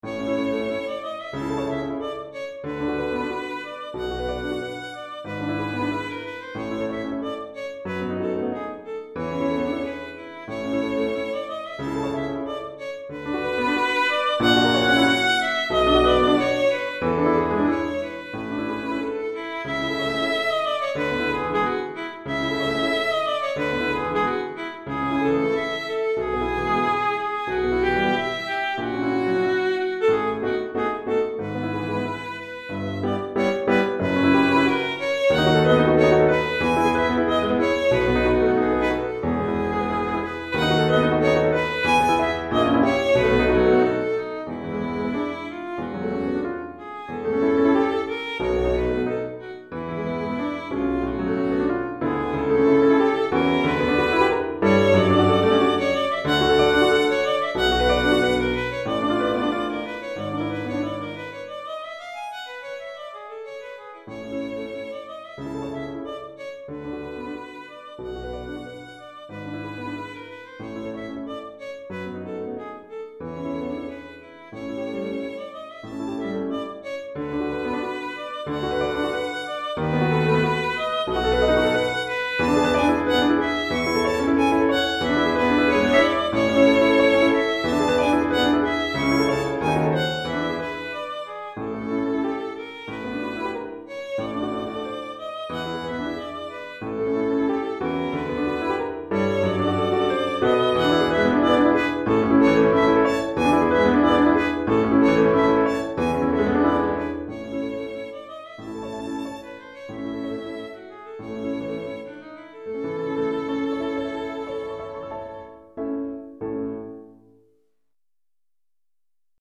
Violon et Piano